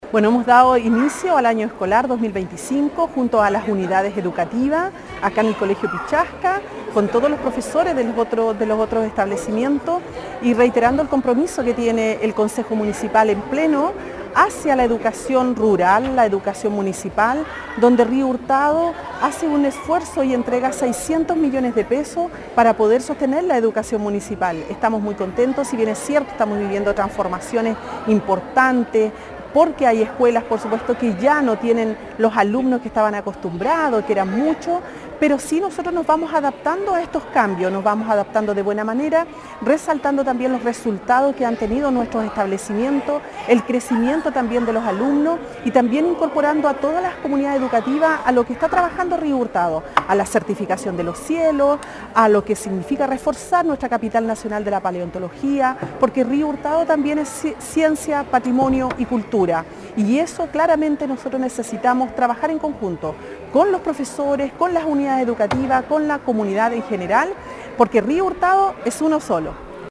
En el Colegio Pichasca, de la comuna de Río Hurtado, la Municipalidad inauguró el año escolar 2025.
La alcaldesa Carmen Juana Olivares recalcó el trabajo y compromiso municipal por la educación, incluso con aportes desde el concejo municipal.